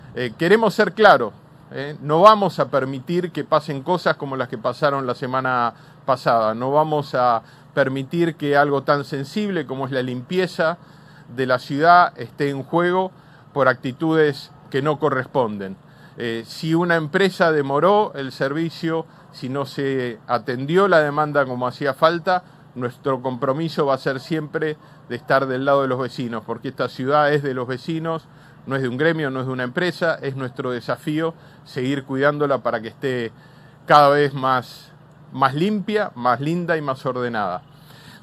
“Aumentamos los controles e impusimos más sanciones a las empresas que prestan servicios, porque las contratamos para eso. De hecho, aumentamos un 150 % las multas a las empresas en estos siete meses. Estamos más encima, estamos controlando más y exigiéndoles más”, dijo el Jefe de Gobierno, Jorge Macri, en la inauguración de la nueva Planta de Tratamiento de residuos mixtos de Villa Soldati.
Audio del Jefe de Gobierno Jorge Macri sobre el Plan Integral de Limpieza e Higiene para la Ciudad de Buenos Aires